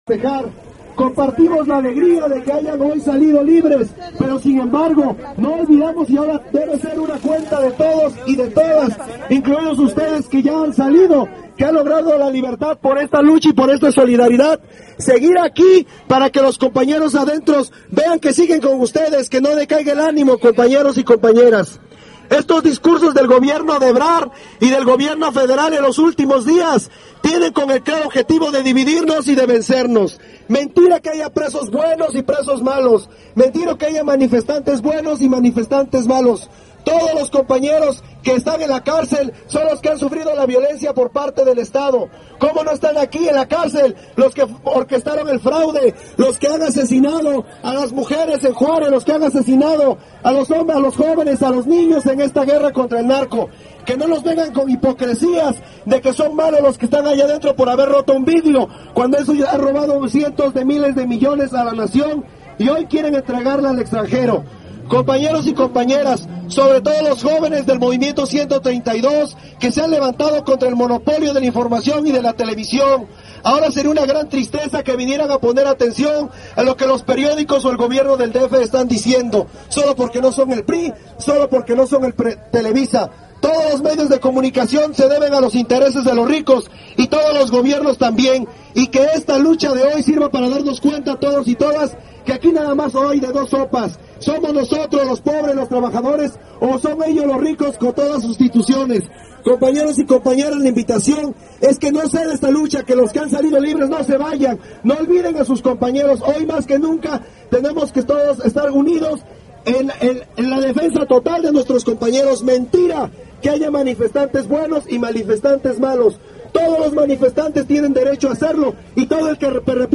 Mitin 9 de Diciembre-Reclusorio Norte